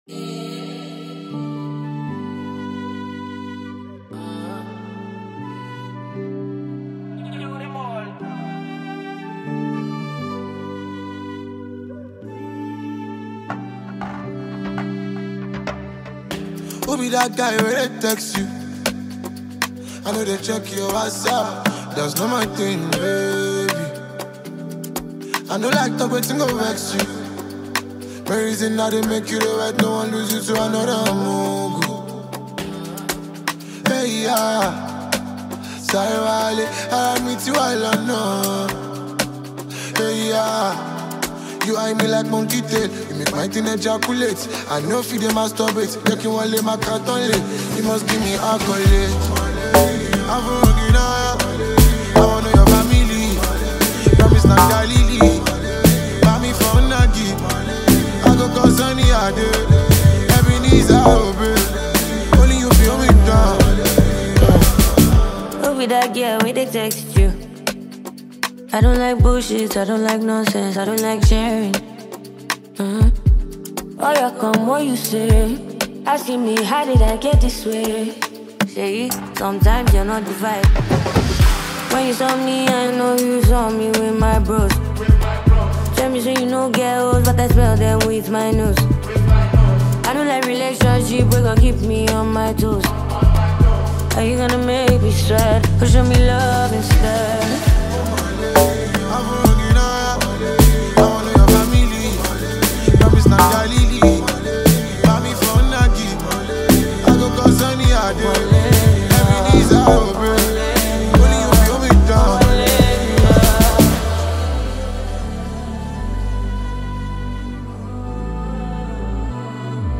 a Nigeria Female Singer-Songwriter.